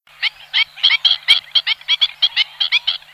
Echasse blanche
himantopus himantopus
echasse.mp3